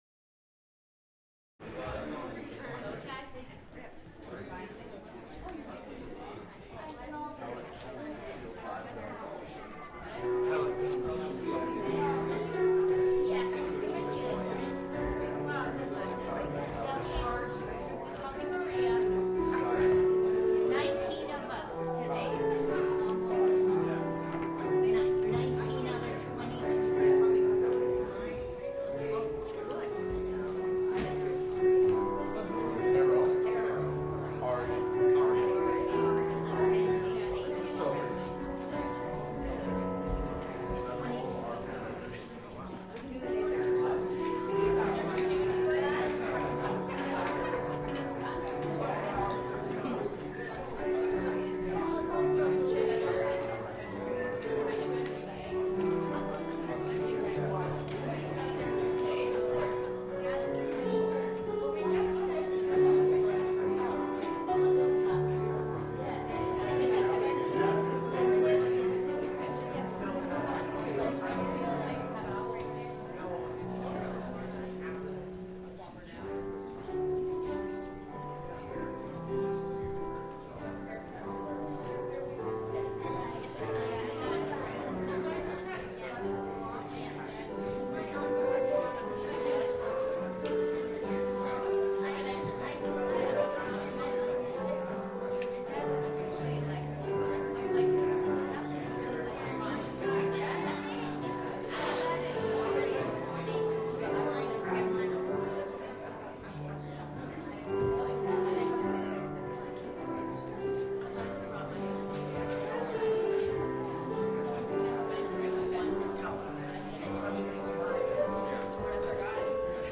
Sylvan Lake Apostolic Lutheran Church